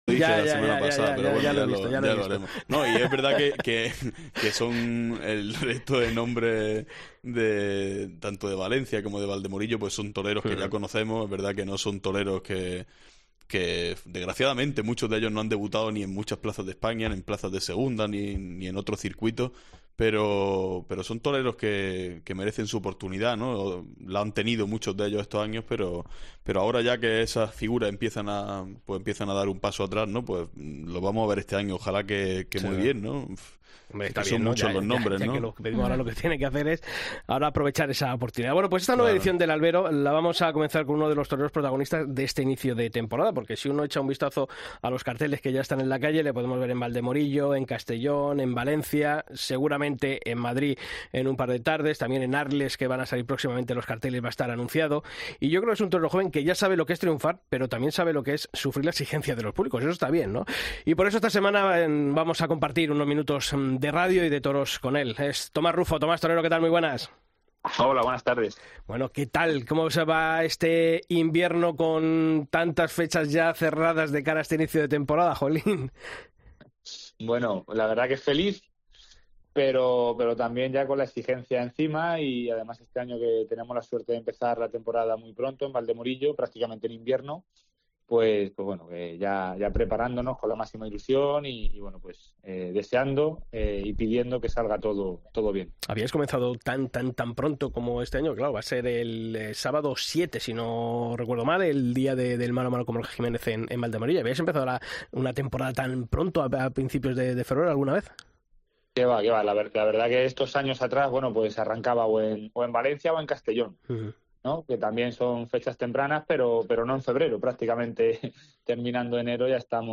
El diestro toledano Tomás Rufo habla en El Albero de COPE de su inicio de temporada en Valdemorillo, de su intención de torear en Madrid la corrida de La Quinta, de su apuesta por la variedad de encastes y de la responsabilidad de los toreros de su generación.